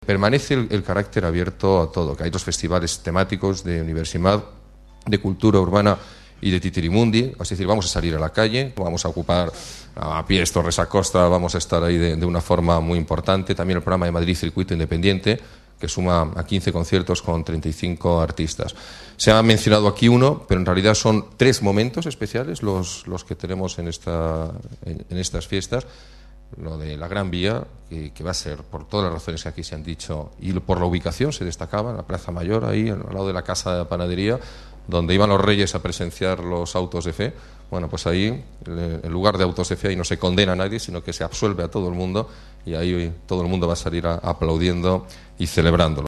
El alcalde, Alberto Ruiz-Gallardón, presenta la programación de las Fiestas de San isidro 2007